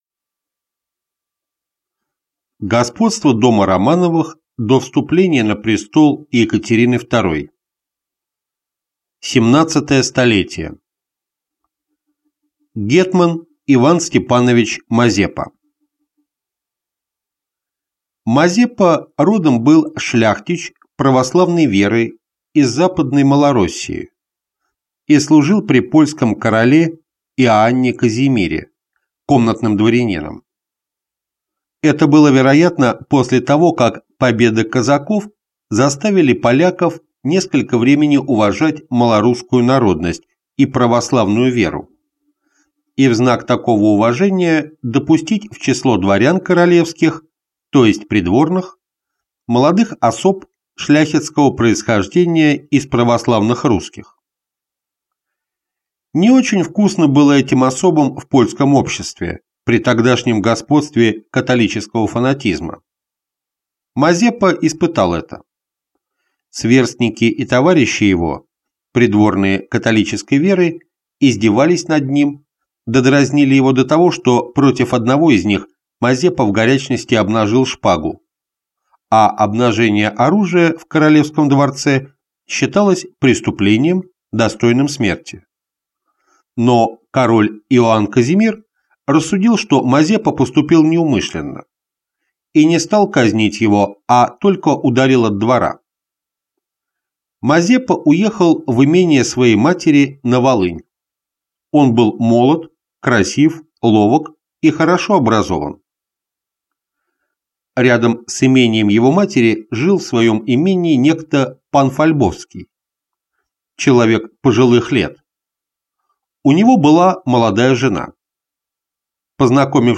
Аудиокнига Русская история. Том 12. От Ивана Мазепы до архиепископа Феофана Прокоповича | Библиотека аудиокниг